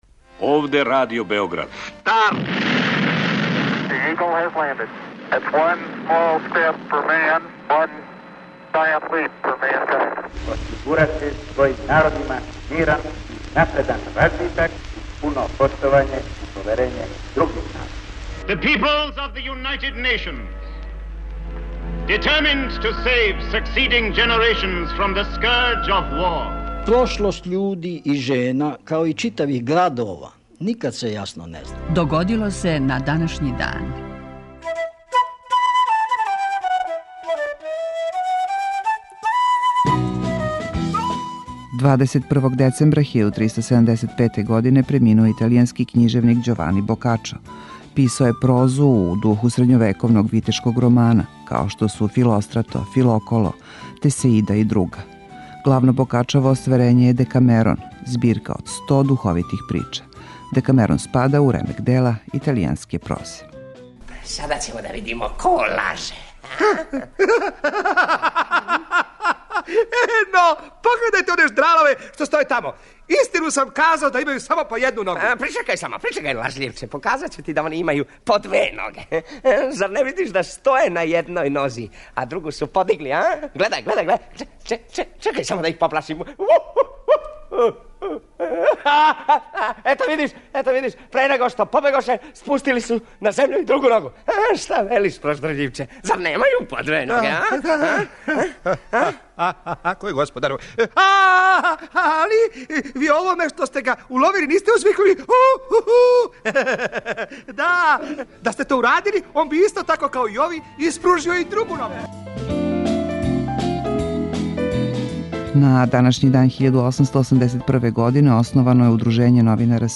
Емисија Догодило се на данашњи дан, једна од најстаријих емисија Радио Београда свакодневни је подсетник на људе и догађаје из наше и светске историје. У 5-томинутном прегледу, враћамо се у прошлост и слушамо гласове људи из других епоха.